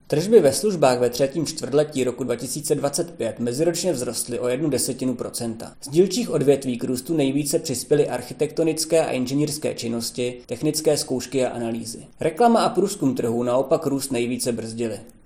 Vyjádření